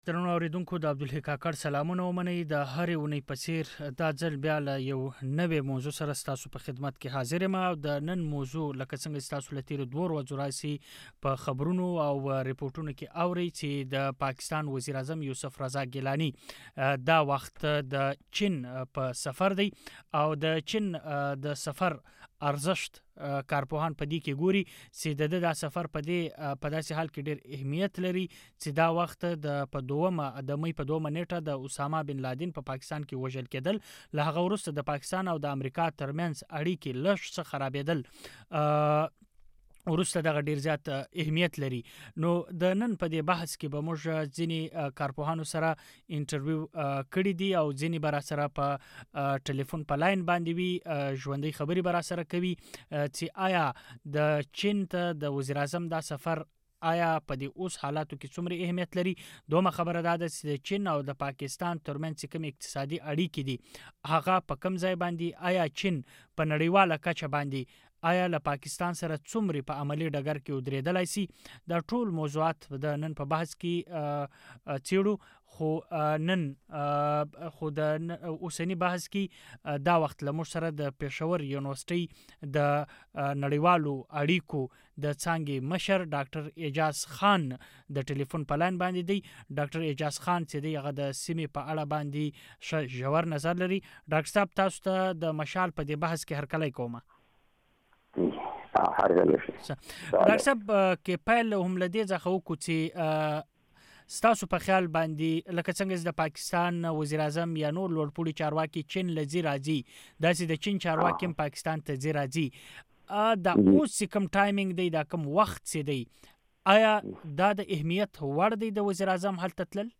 آیا د چین او پاکستان اړیکي سیاسي ارزښت زیات لري که اقتصادي. دا او له دې سره تړلې نورې پوښتنې د مشال تر رڼا لاندې بحث کې اورېدلی شي